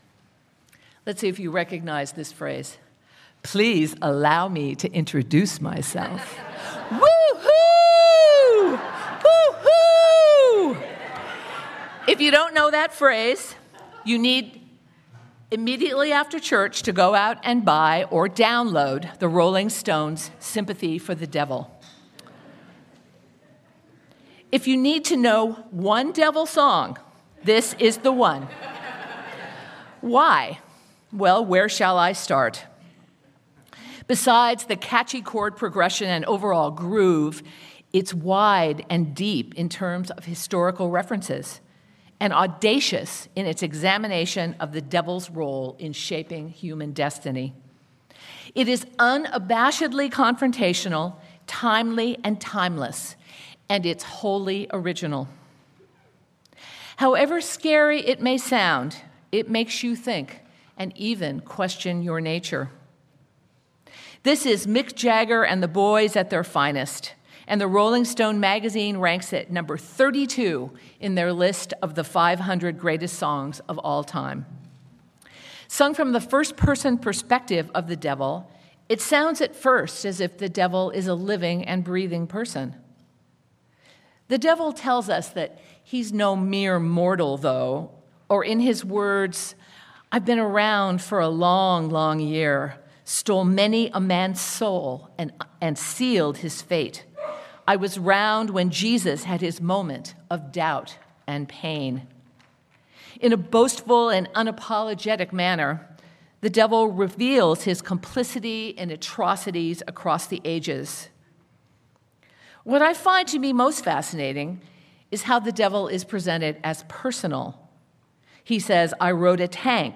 Sermon-The-Devil-is-Alive-and-Well.mp3